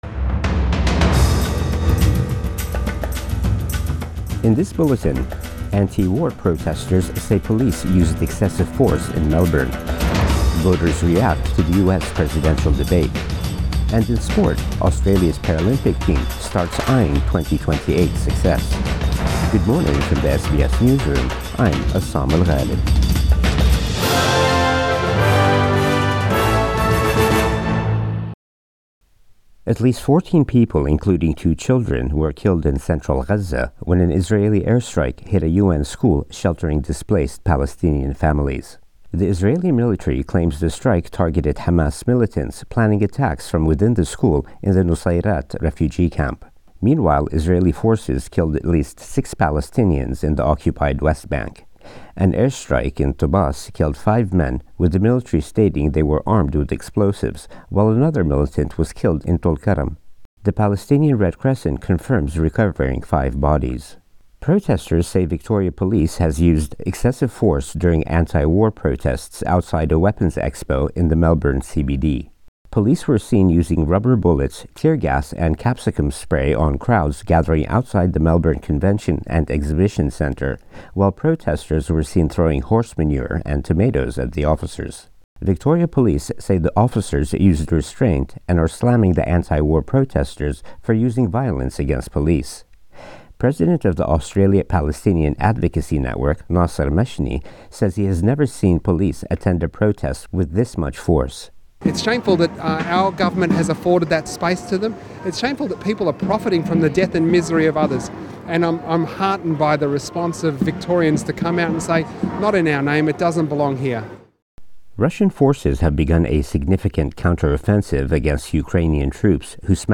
Morning News Bulletin 12 September 2024